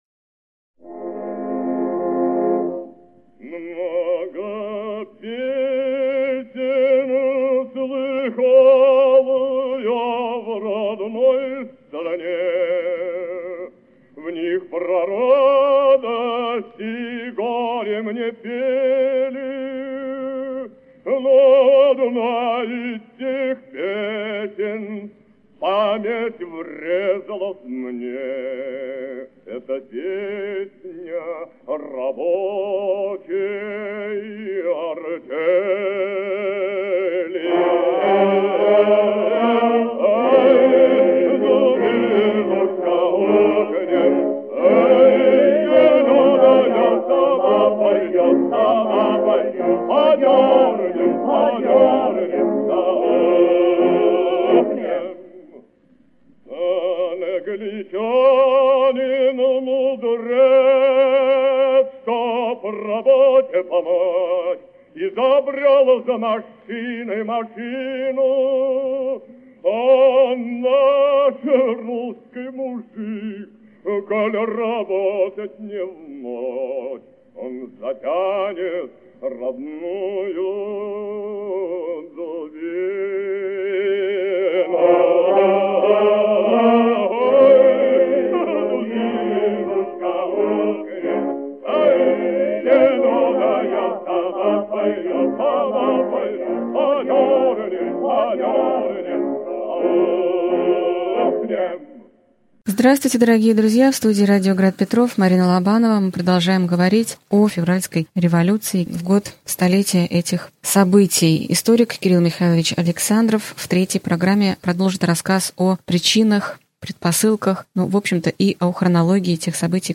Аудиокнига Февральская революция и отречение Николая II. Лекция 3 | Библиотека аудиокниг